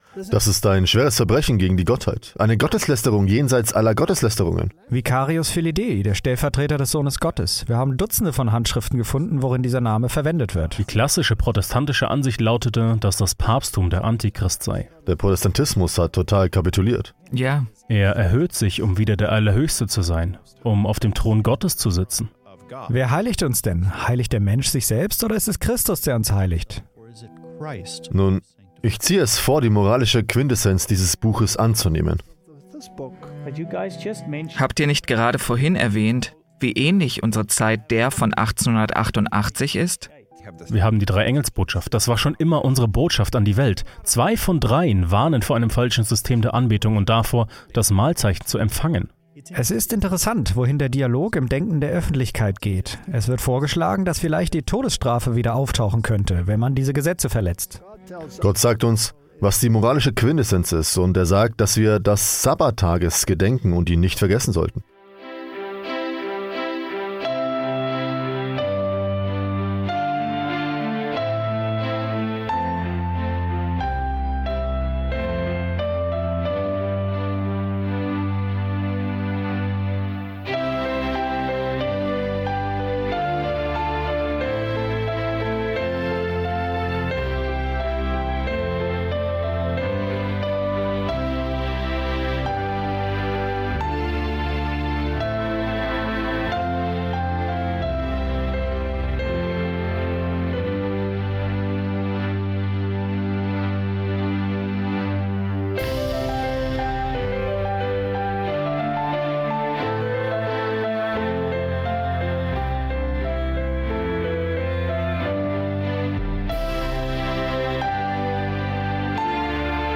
(Voice Over)